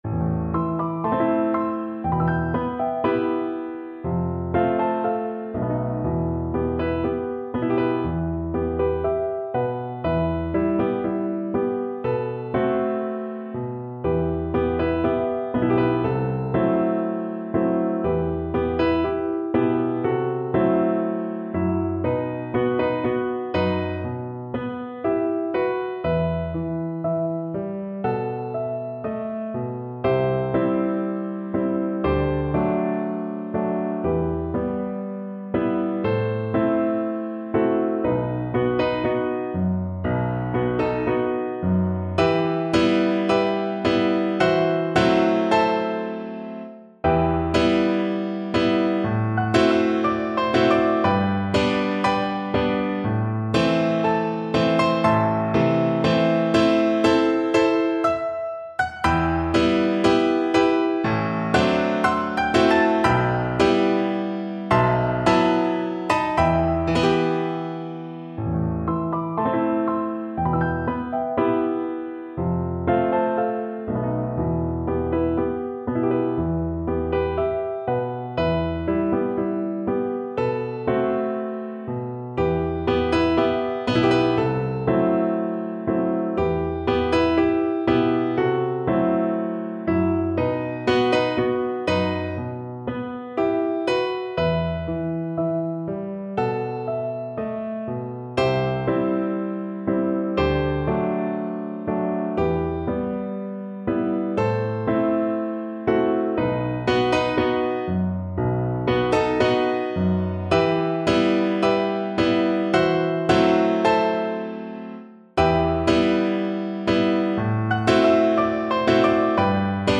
Bassoon version
4/4 (View more 4/4 Music)
Andantino = c. 60 (View more music marked Andantino)
Classical (View more Classical Bassoon Music)